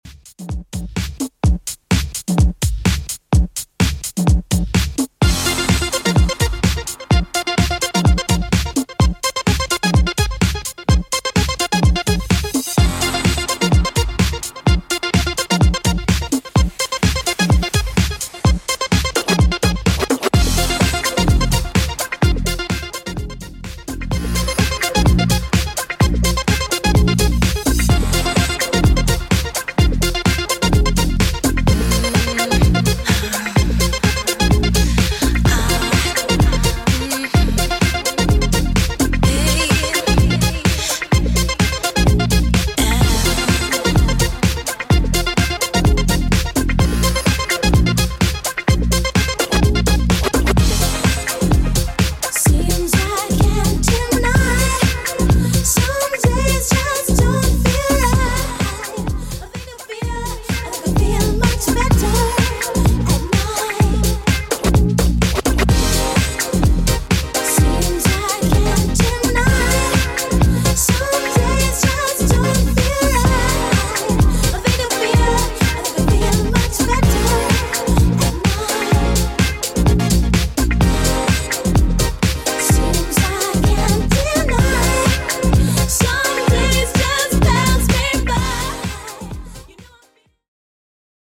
BPM: 127 Time